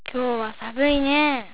ためになる広島の方言辞典 さ．